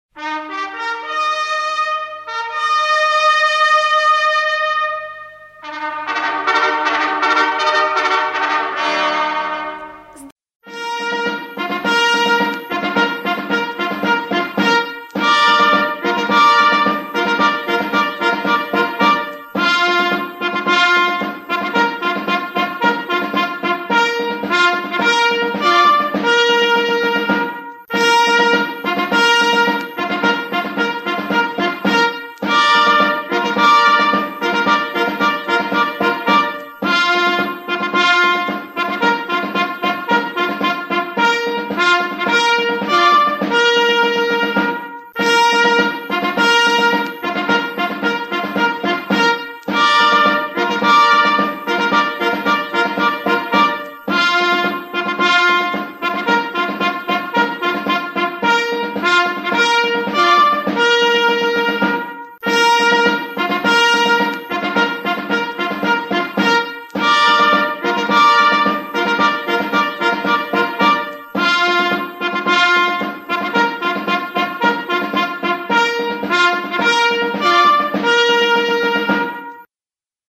а если так.........Марш Торжественный вынос знамени (2 вариант)